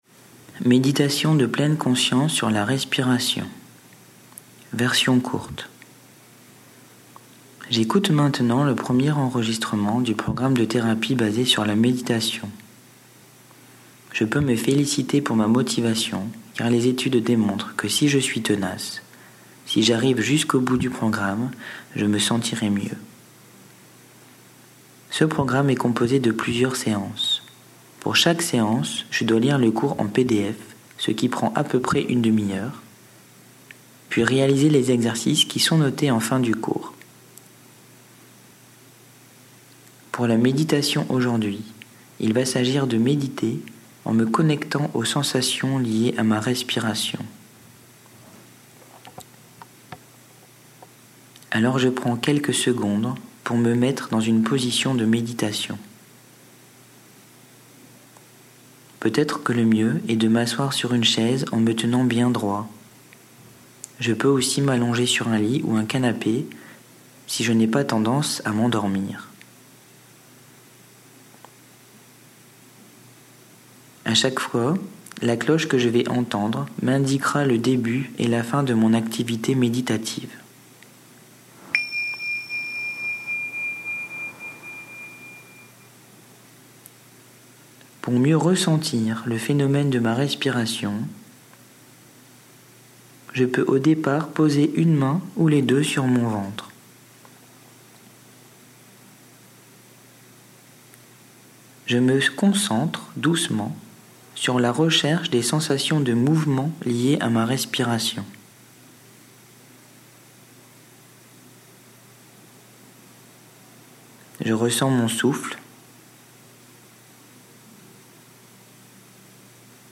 Méditation respiration/version courte